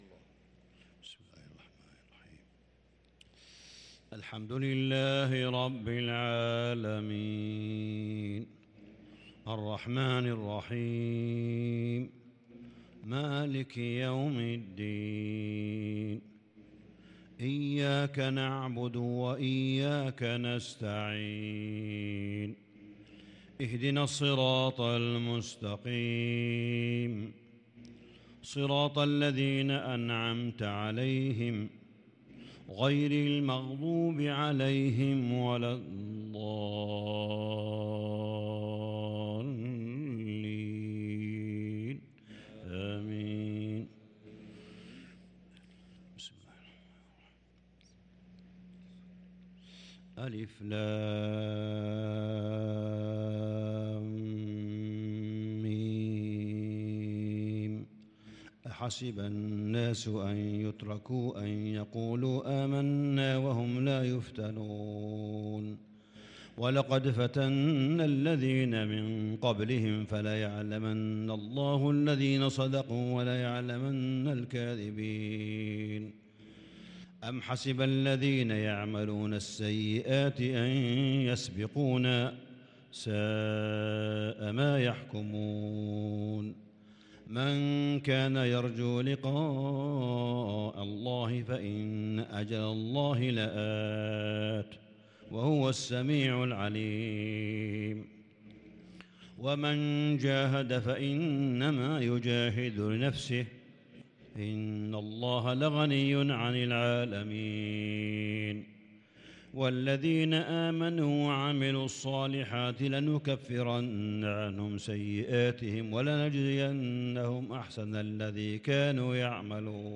صلاة العشاء للقارئ صالح بن حميد 16 رمضان 1443 هـ
تِلَاوَات الْحَرَمَيْن .